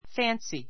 fǽnsi ふァ ンスィ